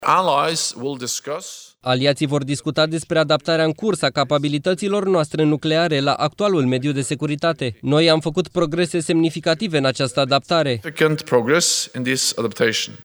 „Aliaţii vor discuta despre adaptarea în curs a capabilităţilor noastre nucleare la actualul mediu de securitate. Noi am făcut progrese semnificative în această adaptare”, a declarat secretarul general al NATO, Jens Stoltenberg.